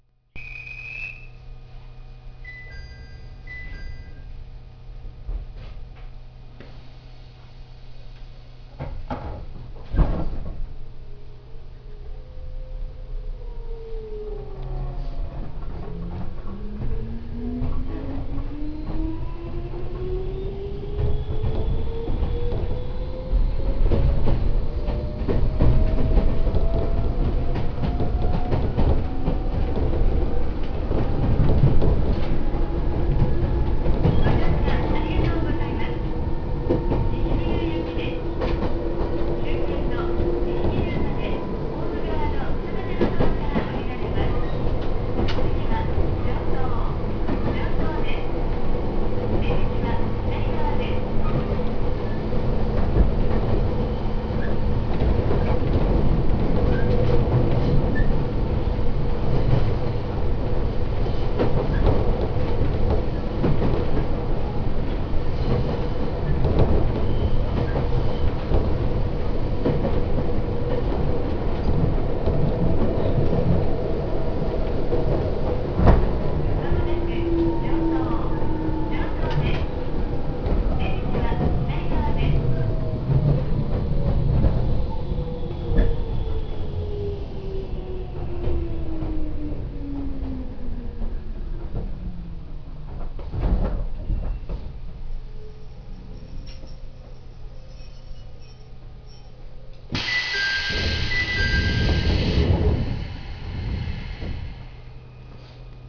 〜車両の音〜
・700型走行音
いかにもな抵抗制御です。ドアチャイムはディーゼルカー等にありがちなものを使用しています。車内放送も、地方のワンマン列車によくある声です。ワンマンか否かは関係なしに自動放送を使用しているようです。